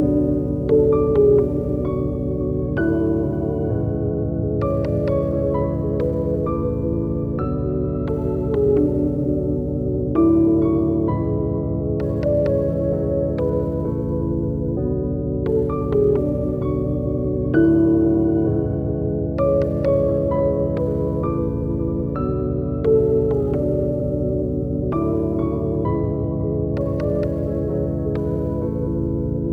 Perfect for Trap, but works well with R&B and Pop too. Dark and deep textures to bring more fill to your songs.